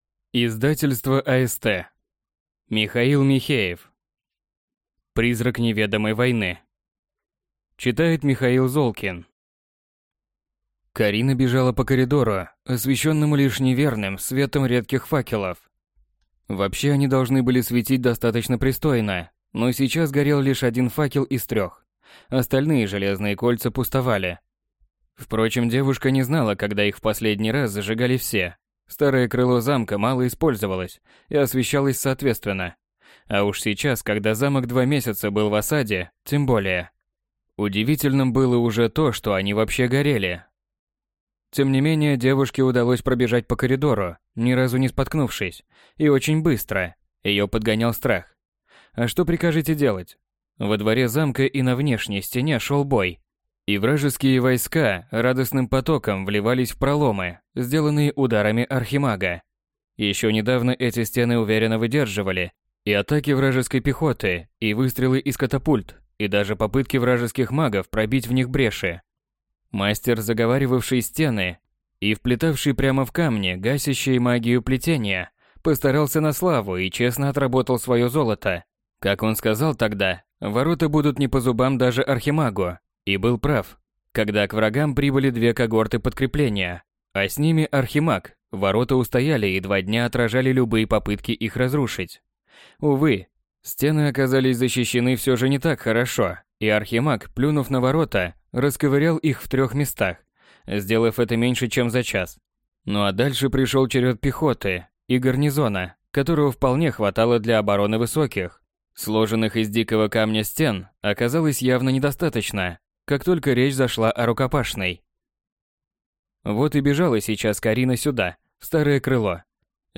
Аудиокнига Призрак неведомой войны. Книга 1 | Библиотека аудиокниг